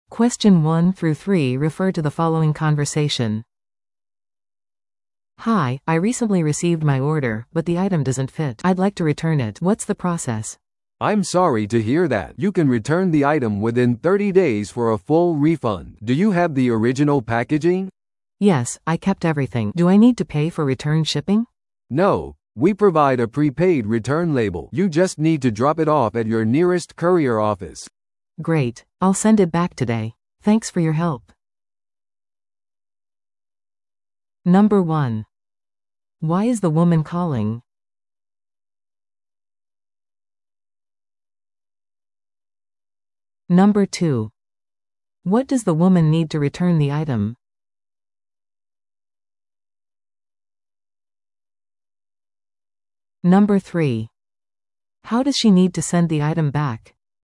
No.1. Why is the woman calling?